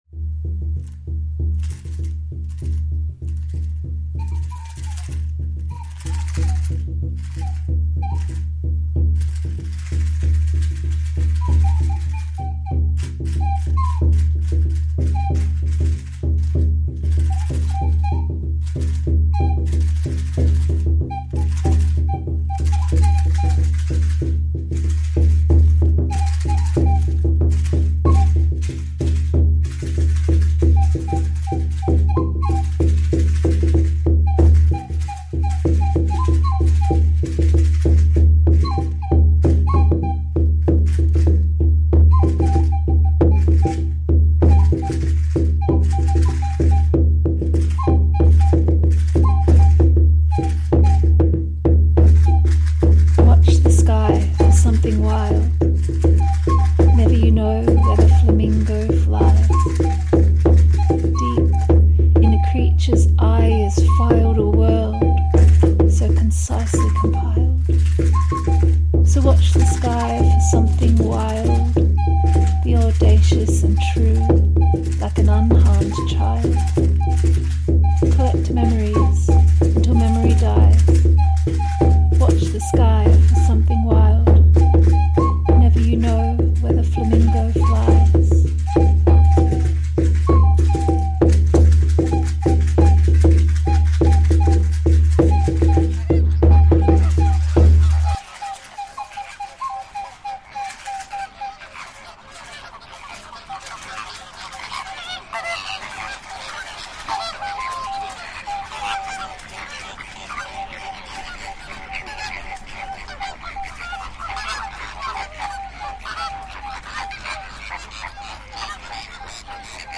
I mimicked their clatter and calls with rattle, whistle and drum.
Flamingoes in Camargue reimagined